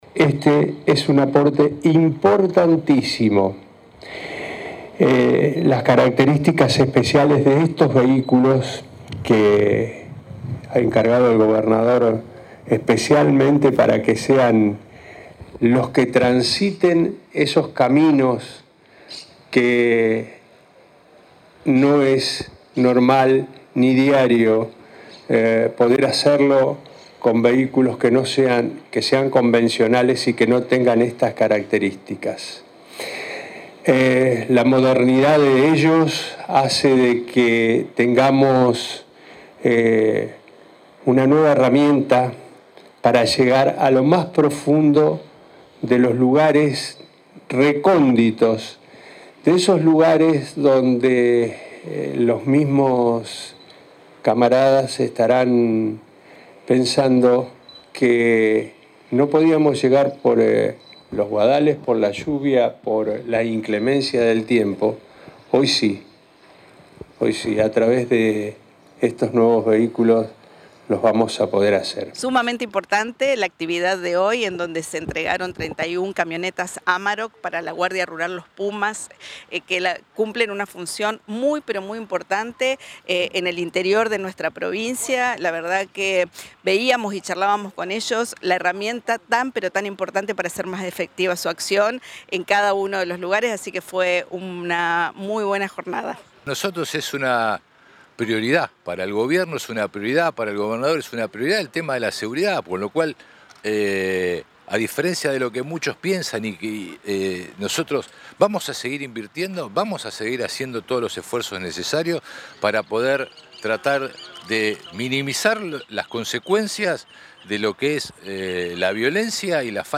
En el acto que se desarrolló este viernes en el espacio La Redonda de la ciudad de Santa Fe, el ministro de Seguridad, Rubén Rimoldi, señaló que "es una deuda que teníamos y seguimos teniendo porque para el campo desde la seguridad nunca podemos terminar de completar la contención para tan basto espacio.
Los ministros Rimoldi, Arena y Corach durante la actividad.